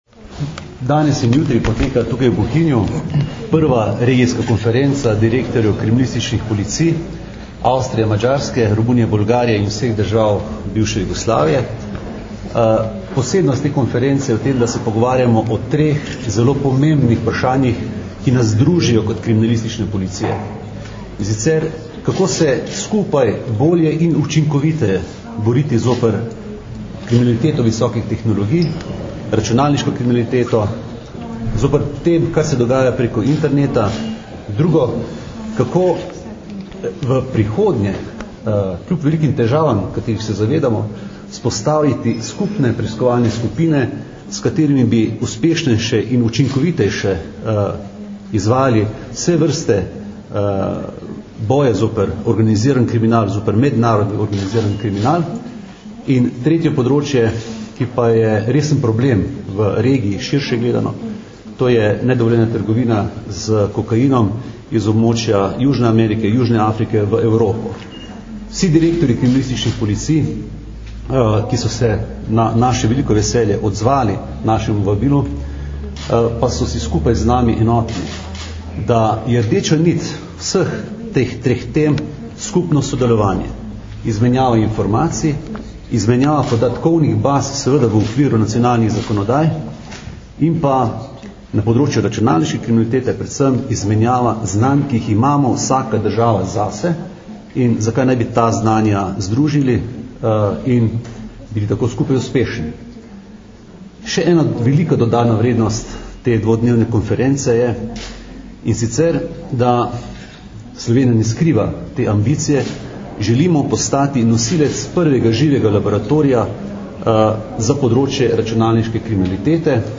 Nagovor direktorja Uprave kriminalistične policije mag. Aleksandra Jevška (velja govorjena beseda)
Zvočni posnetek izjave mag. Aleksandra Jevška (mp3)